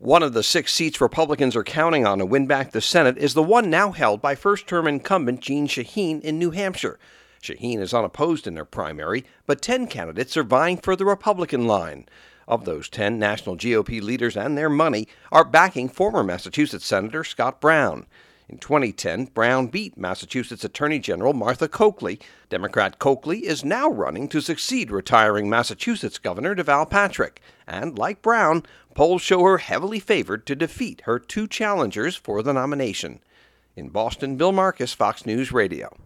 HAS MORE FROM BOSTON.